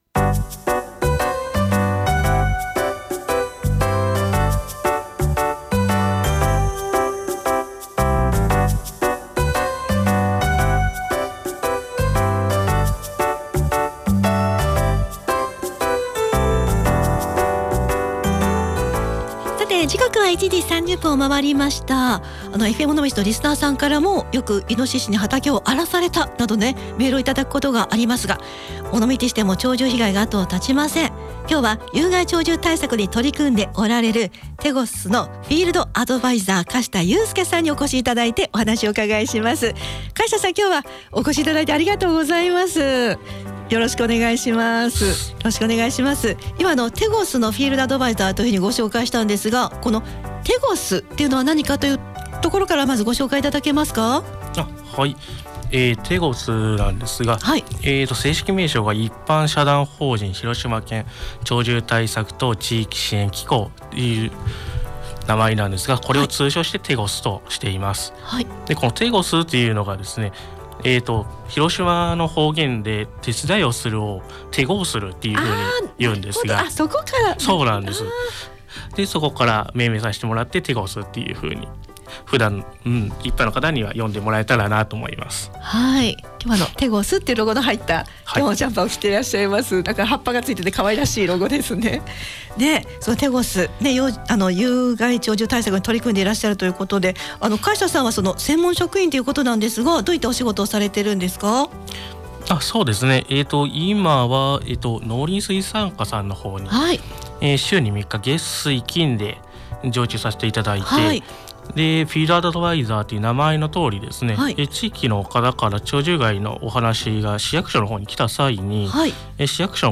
尾道の鳥獣被害、有効な対策やtegosによる支援などについて、お聞きしました。